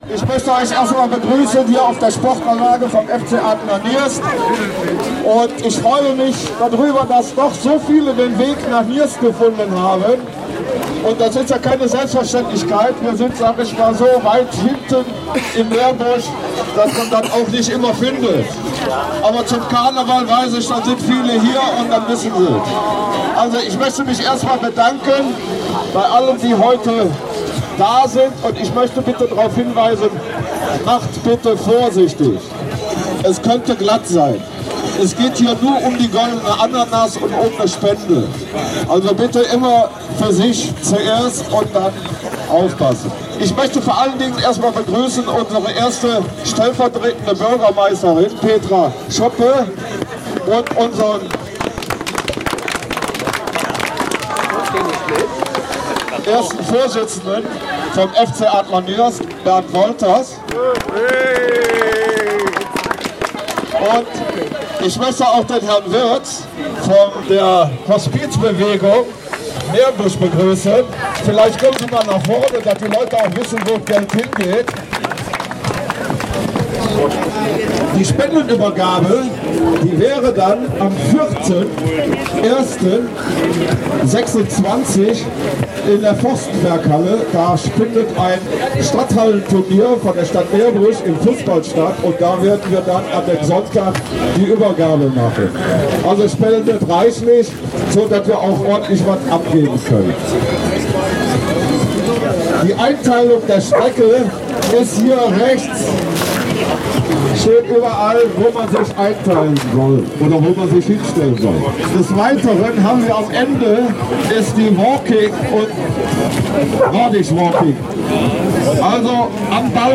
9. Meerbuscher Silvesterlauf